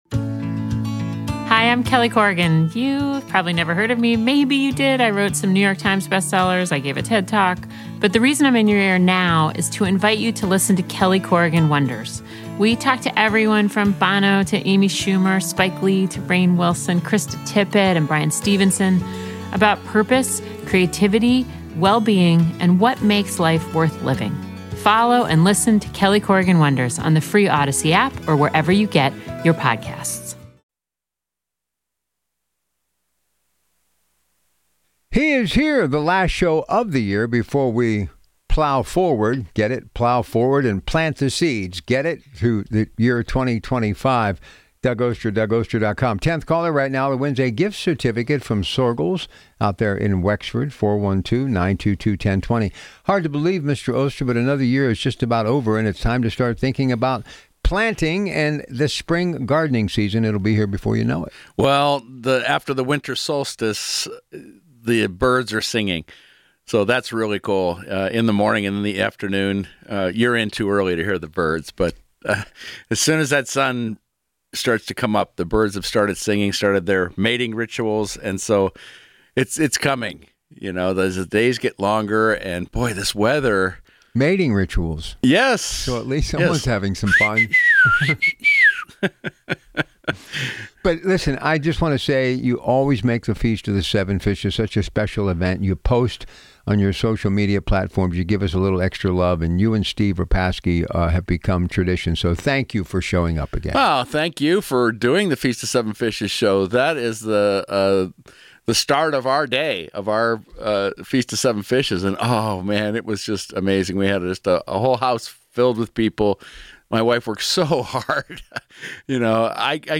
The Organic Gardener airs live Sundays at 7:00 AM on KDKA Radio.